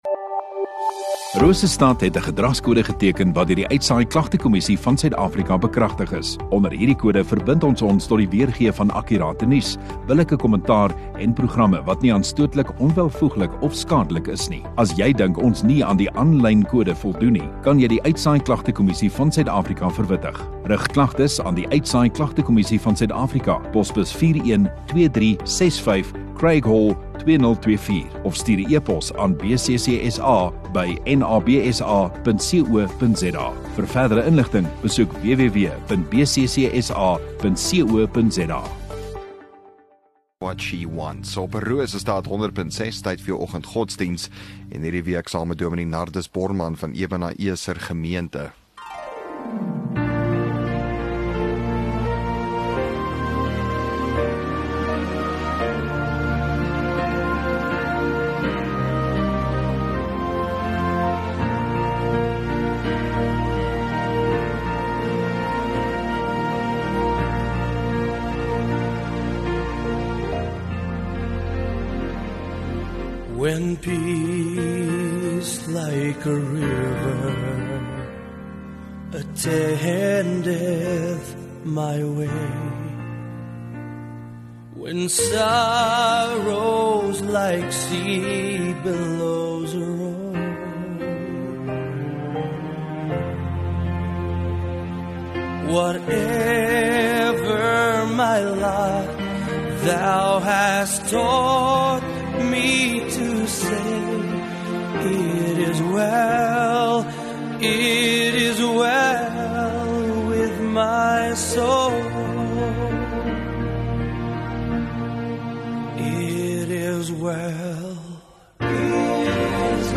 26 May Maandag Oggenddiens